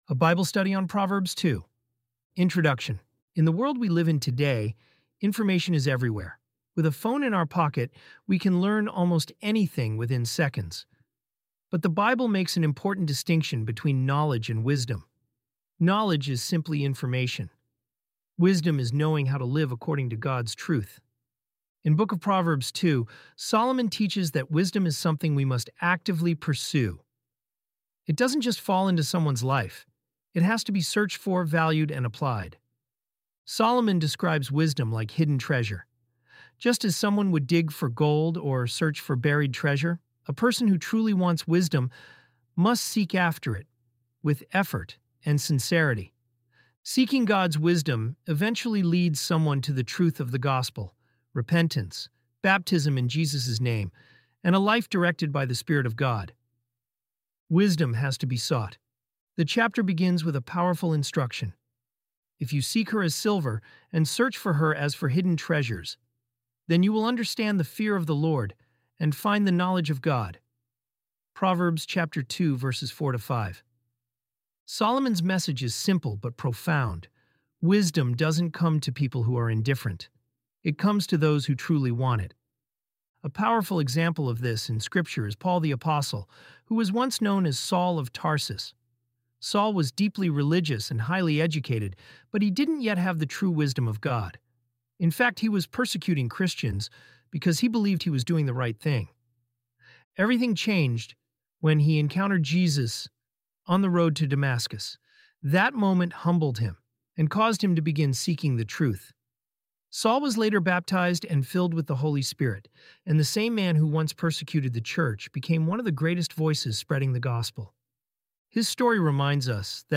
ElevenLabs_proverbs_2.mp3